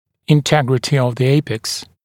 [ɪn’tegrətɪ əv ðə ‘eɪpeks][ин’тэгрэти ов зэ ‘эйпэкс]целостность верхушки корня (зуба)